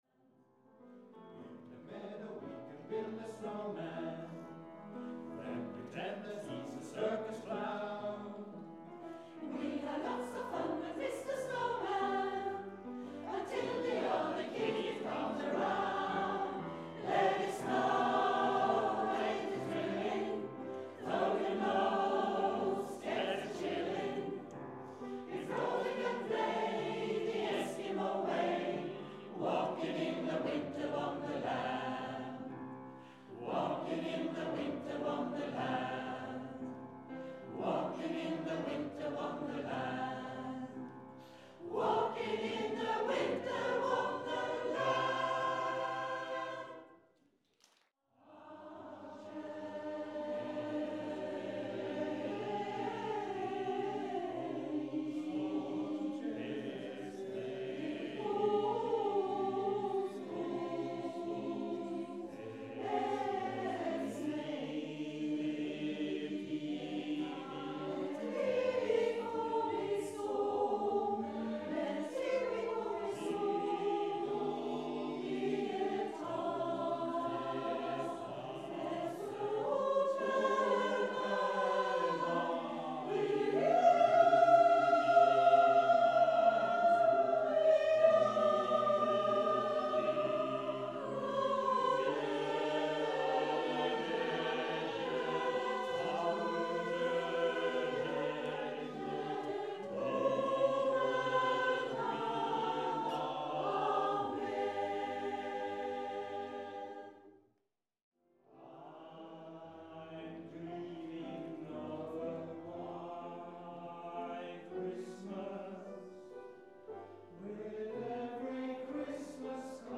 JULEKONSERT
Et nydelig damekor med fortryllende sang.
Julekonserten med Kolbotnkoret er kjent for å skape julestemning med julesanger.
Det var utrolig flott sunget av mannskoret
Bare hør selv ( små lydopptak fra julekonserten)
Litt-lyd-fra-4-sanger-5des24.mp3